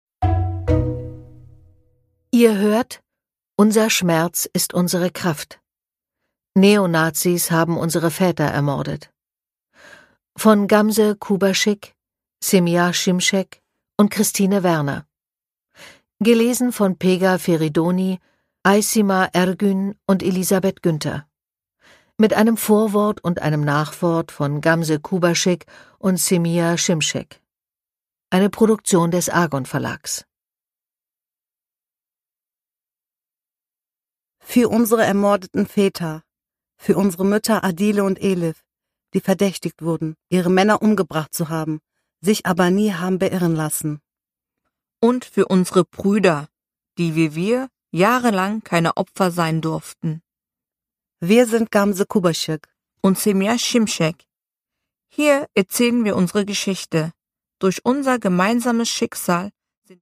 Produkttyp: Hörbuch-Download
Erzählendes Sachhörbuch ab 14 Jahren über die Morde des NSU, authentisch und emotional erzählt.